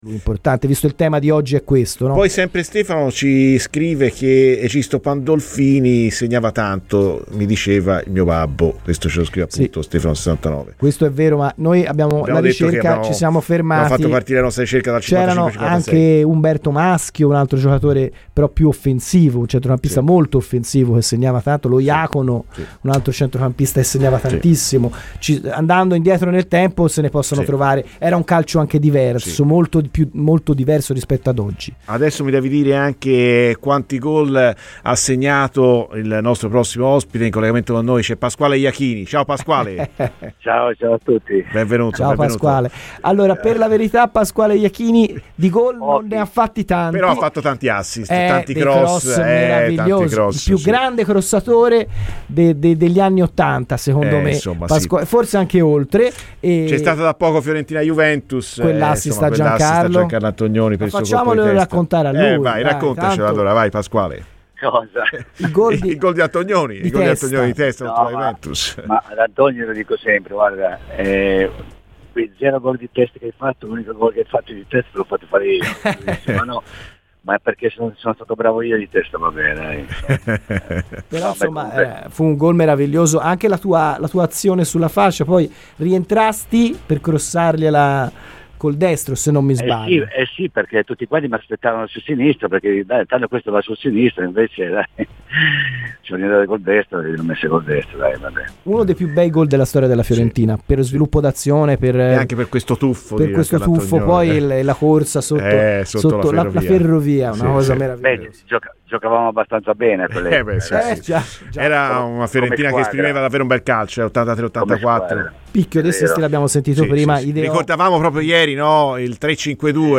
Il calcio è strano, perchè quando vai in quell'imbuto diventa pericoloso" Ascolta il podcast per l'intervista completa